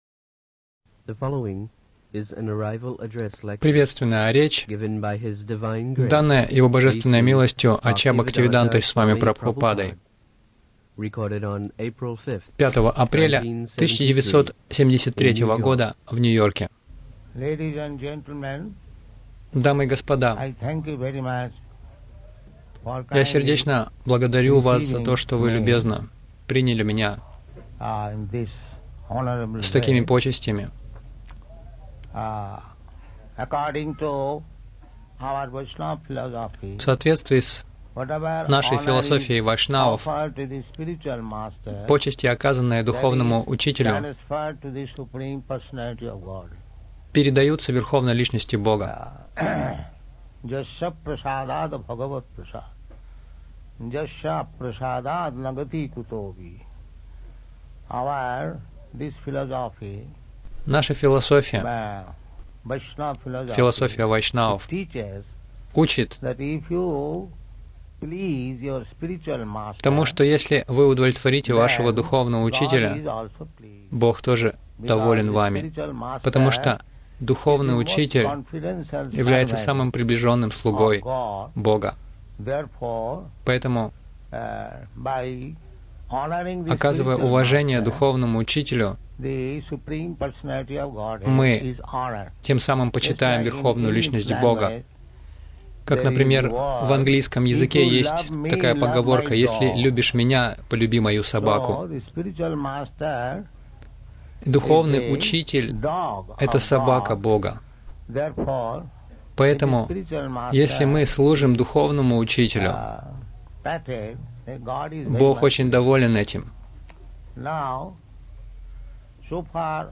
Лекция после прибытия — Мы готовим интеллектуалов общества